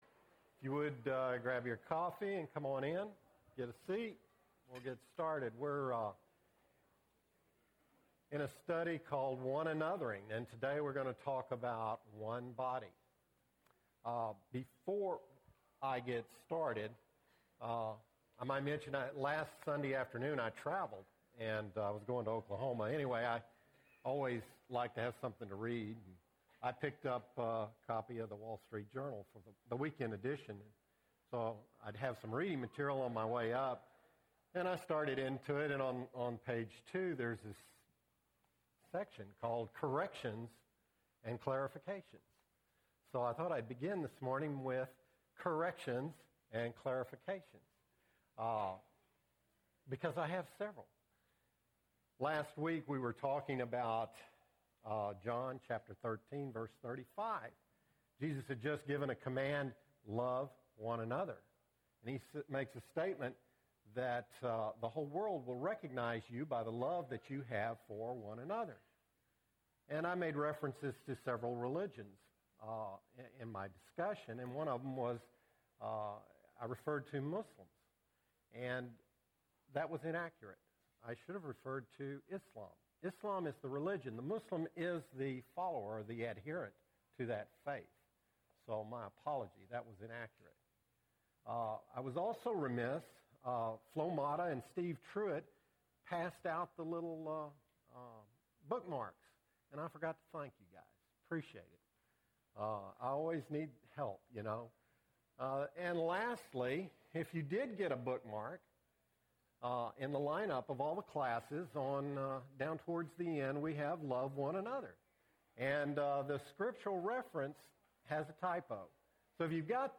One Another-ing (2 of 13) – Bible Lesson Recording
Sunday AM Bible Class